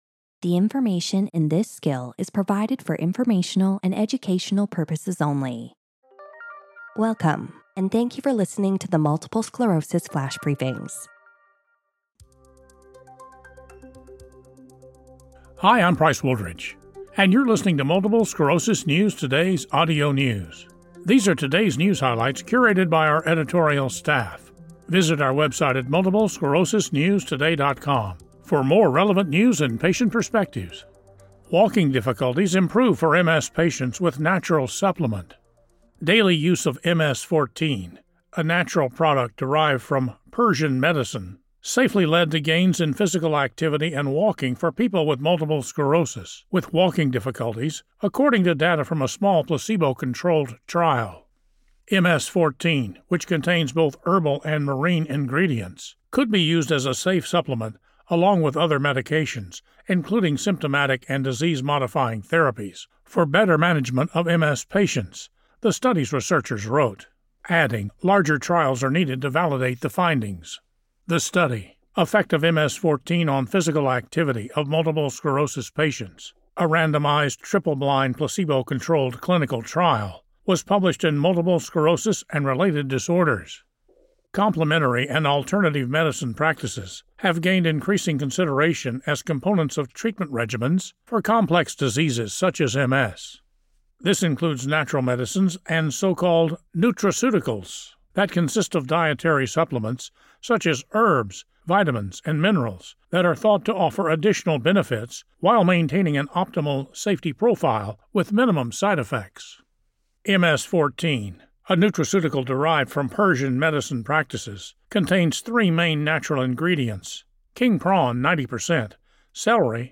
reads the news about a natural supplement from Persian medicine that led to improvements in physical activity for MS patients with mobility issues.